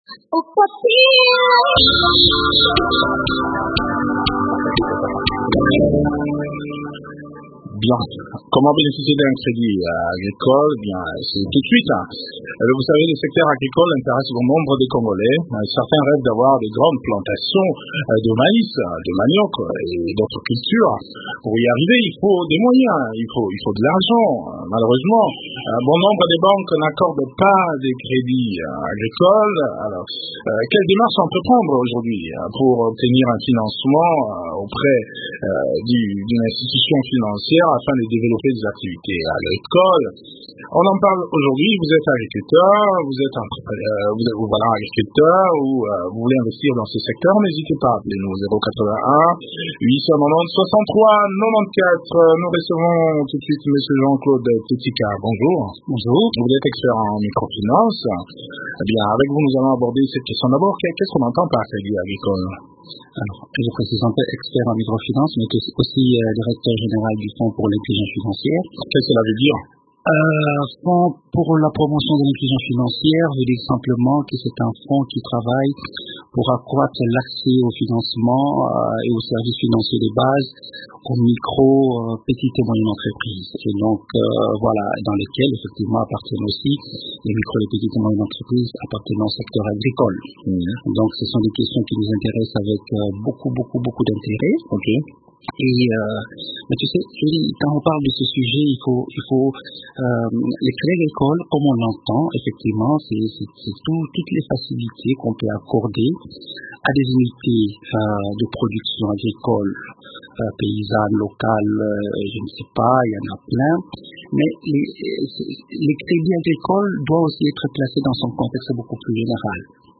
expert en micro finance répond aux questions des auditeurs dans cet entretien